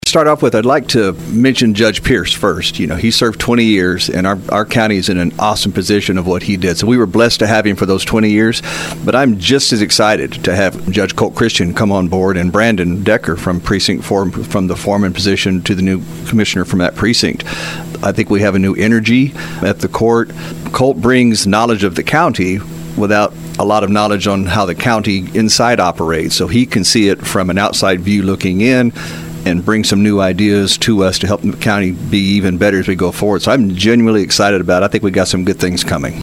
We had an informative interview with our “Around Town” public affairs guest, Walker County Precinct #3 Commissioner Bill Daugette, broadcast over the weekend.